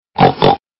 猪 | 健康成长
zhujiao.mp3